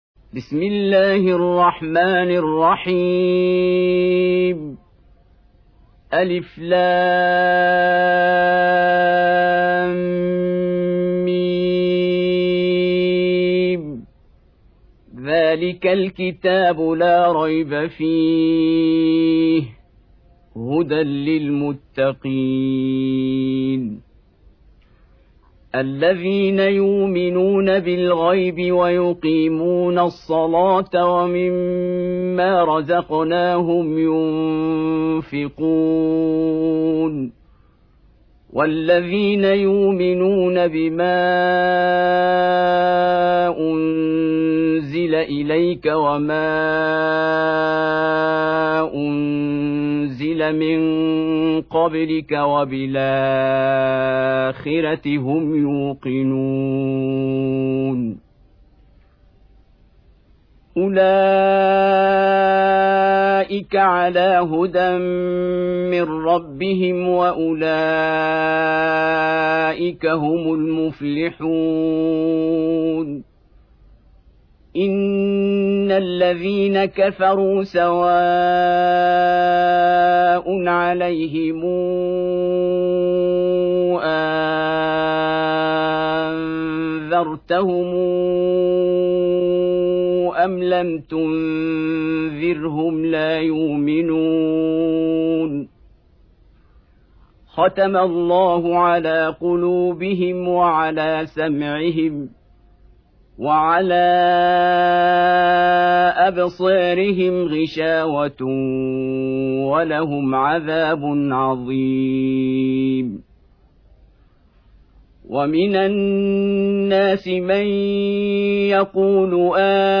2. Surah Al-Baqarah سورة البقرة Audio Quran Tarteel Recitation
Surah Repeating تكرار السورة Download Surah حمّل السورة Reciting Murattalah Audio for 2.